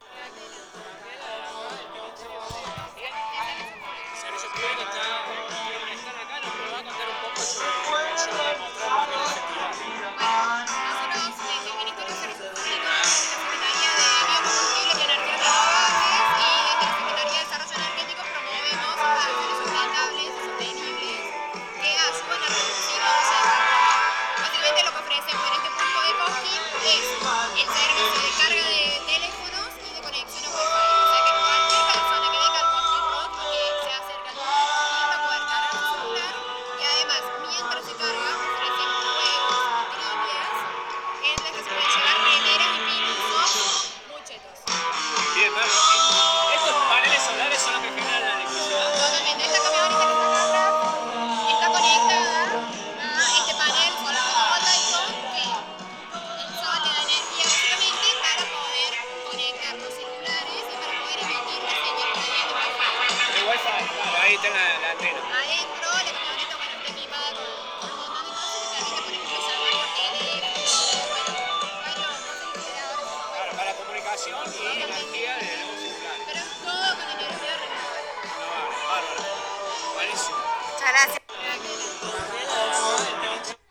Radio del Monte en el Cosquín Rock 2023